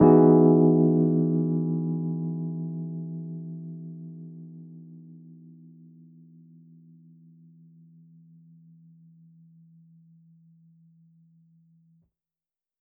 JK_ElPiano3_Chord-Emaj9.wav